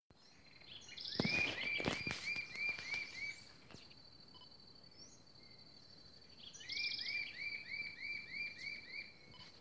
Red-billed Scythebill (Campylorhamphus trochilirostris)
Life Stage: Adult
Condition: Wild
Certainty: Observed, Recorded vocal